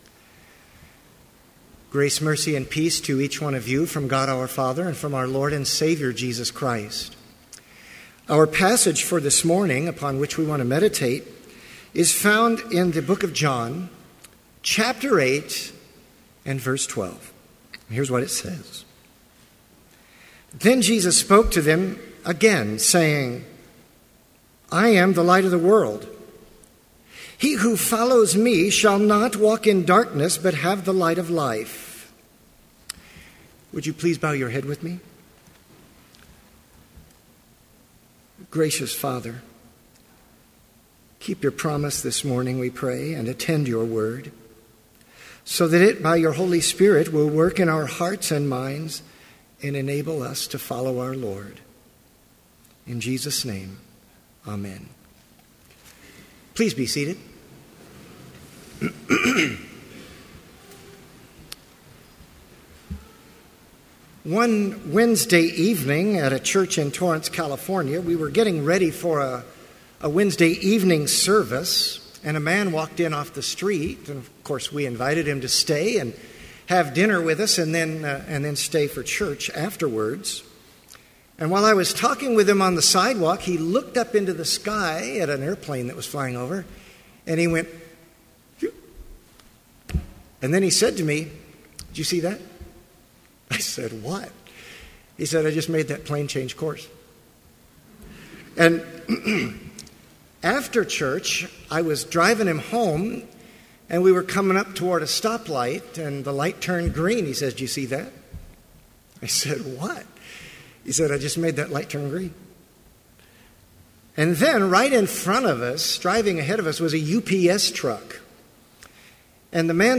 Complete Service
• Hymn 202, Thou, Whose Almighty Word
• Homily
This Chapel Service was held in Trinity Chapel at Bethany Lutheran College on Friday, August 29, 2014, at 10 a.m. Page and hymn numbers are from the Evangelical Lutheran Hymnary.